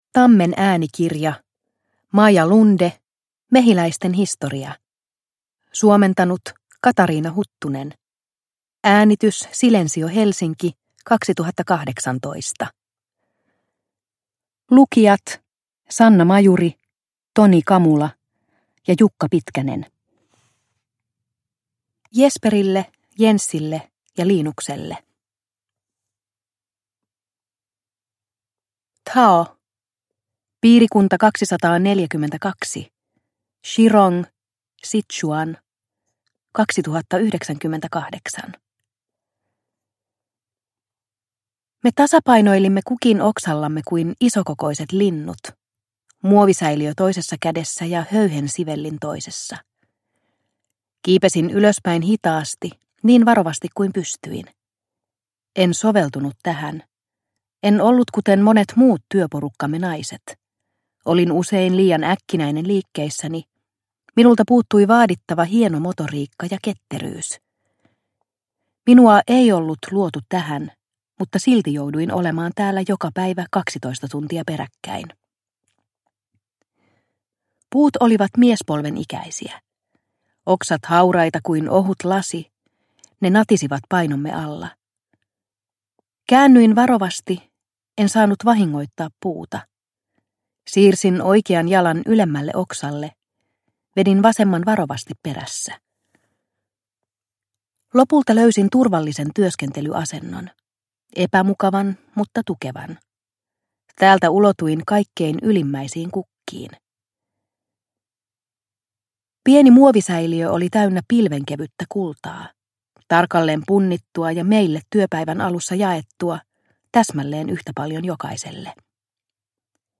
Mehiläisten historia – Ljudbok – Laddas ner